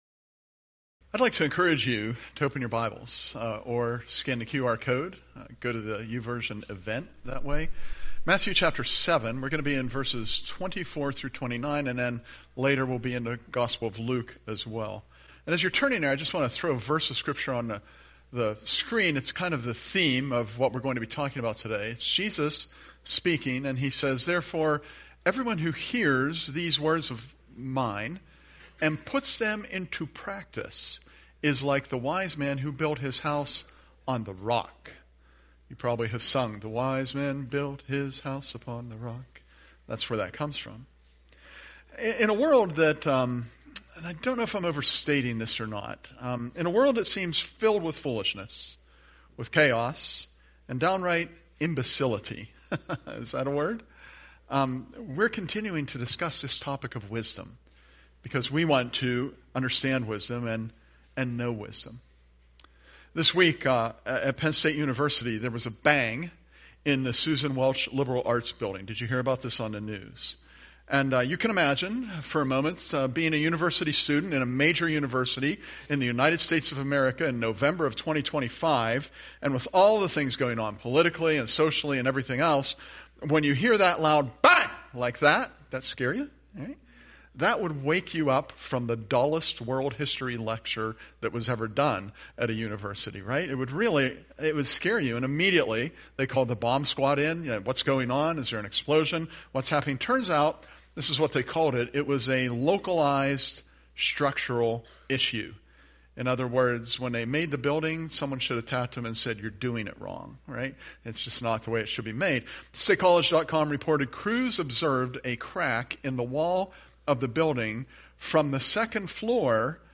Presented at Curwensville Alliance